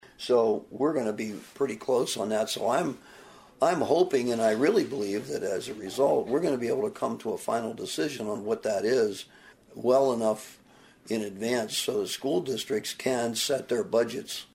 STATE REPRESENTATIVE BOB HENDERSON OF SIOUX CITY THINKS THE HOUSE PROPOSAL WILL BE SOMEWHERE IN THAT RANGE: